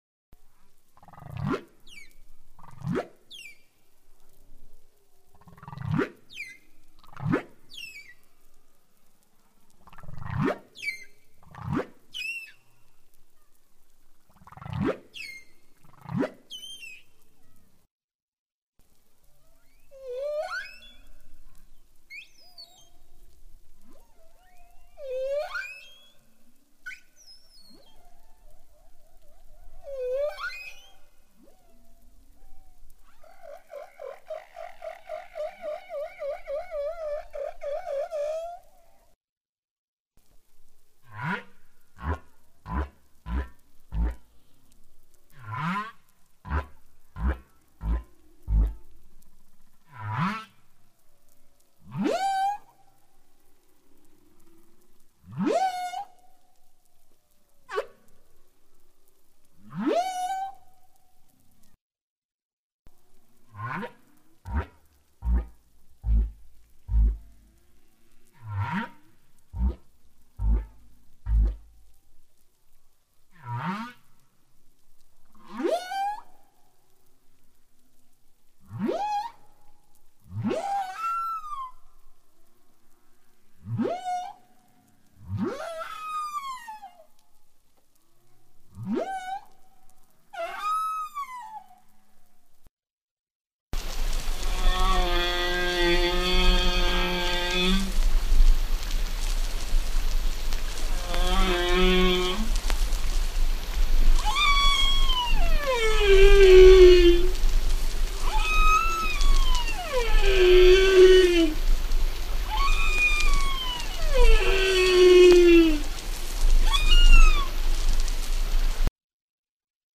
It seemed like a day to go with the whales, rather than the pols, so I hunted up these travelling humpback songs for you, finding them in the Wired story on this cetacean phenom.
humpback_songs.mp3